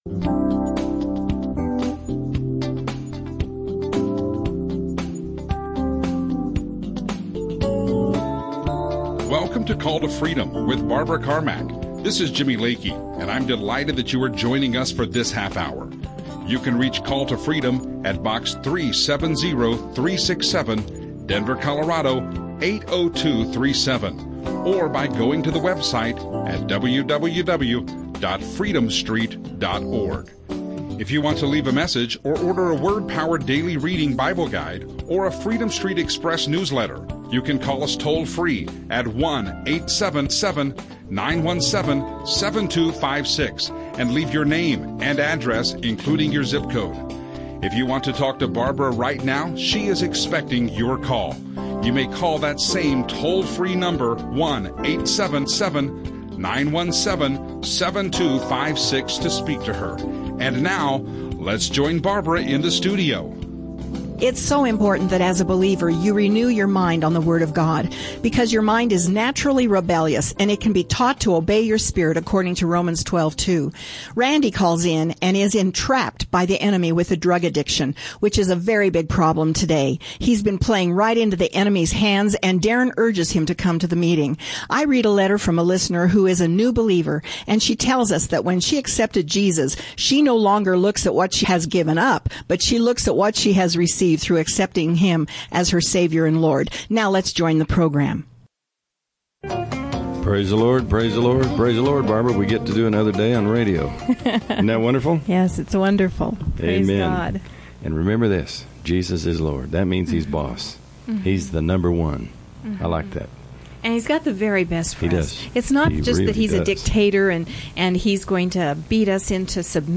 Call to Freedom live radio show